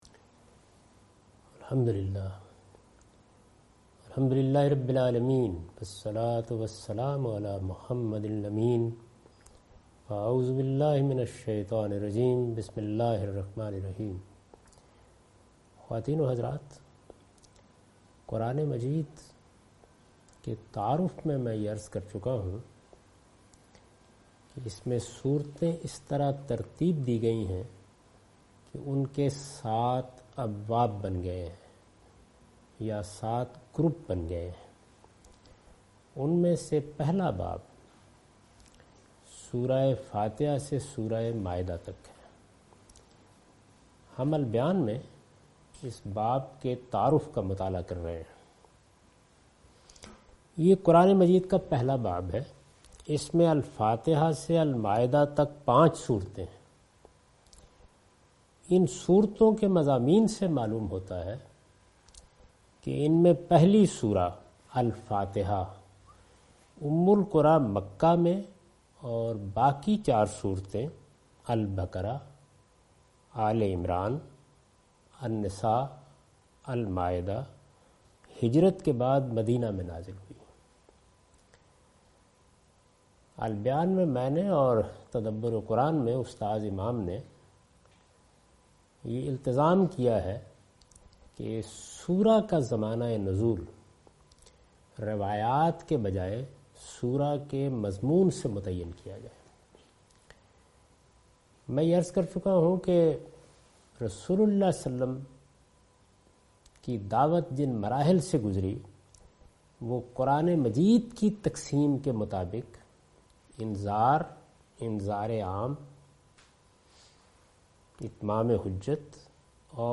A comprehensive course on Quran, wherein Javed Ahmad Ghamidi teaches his tafseer ‘Al Bayan’.